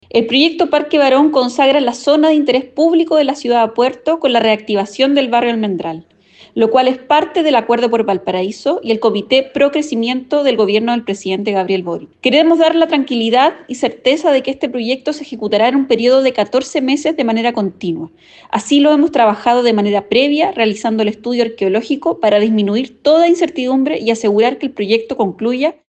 En tanto, la seremi Minvu, Belén Paredes, señaló que la inversión que se está realizando es la más grande de la historia del Ministerio en la región de Valparaíso.